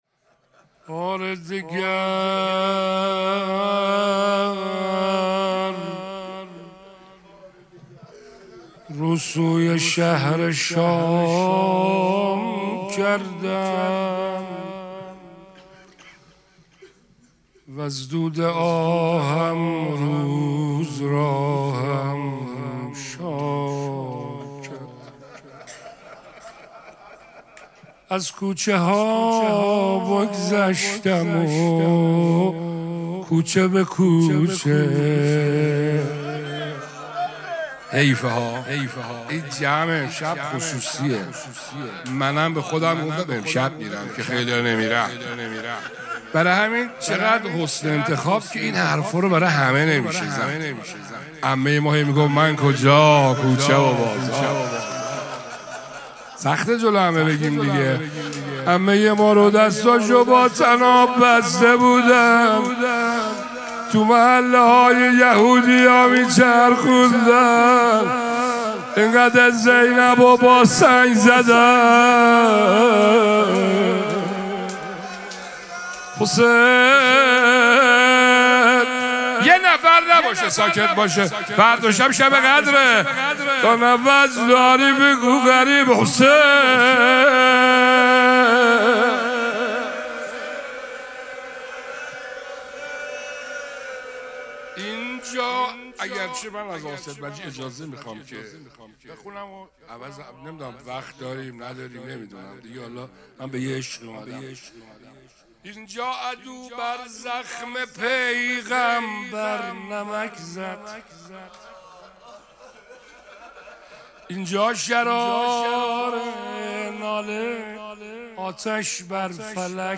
مراسم مناجات شب هجدهم ماه مبارک رمضان سه‌شنبه‌ ۲۸ اسفند ماه ۱۴۰۳ | ۱۷ رمضان ۱۴۴۶ حسینیه ریحانه الحسین سلام الله علیها
سبک اثــر روضه